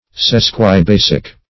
Search Result for " sesquibasic" : The Collaborative International Dictionary of English v.0.48: Sesquibasic \Ses`qui*ba"sic\, a. [Sesqui- + basic.]